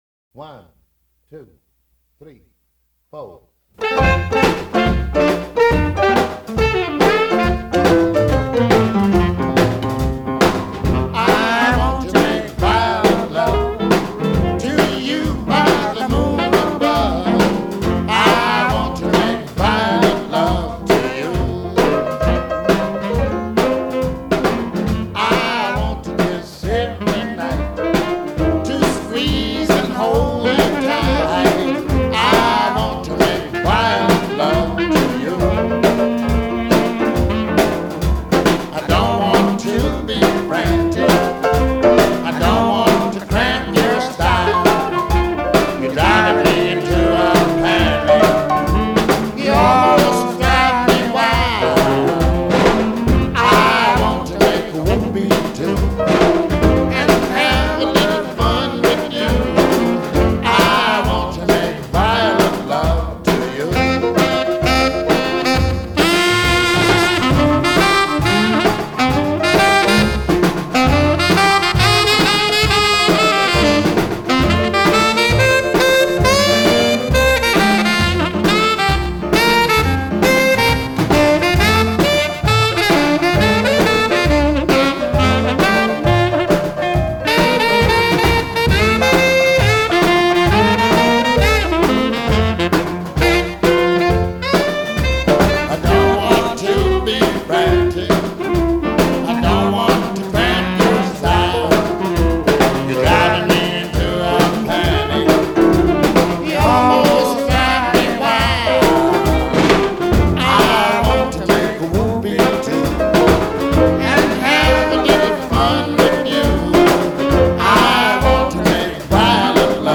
piano, vocals
guitar, vocals
upright bass, vocals
blues standards